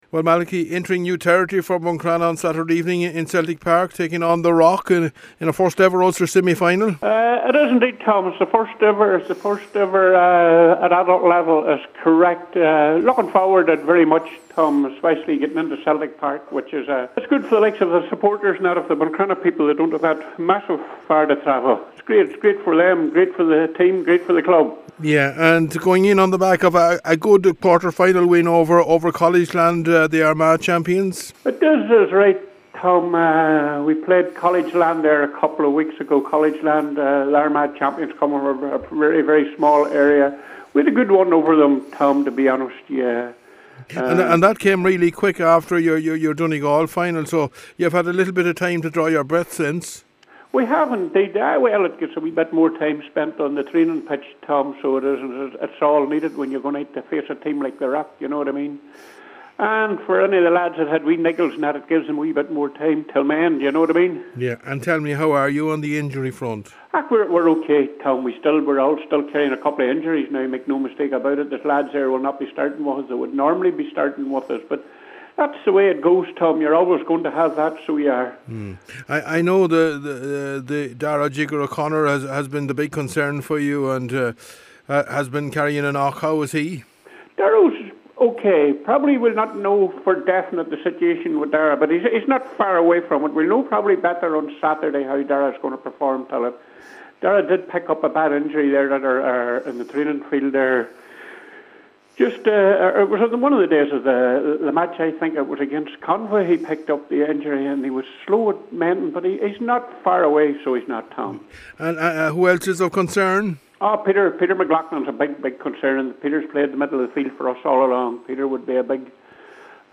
GAA Preview – Buncrana one game from Ulster Final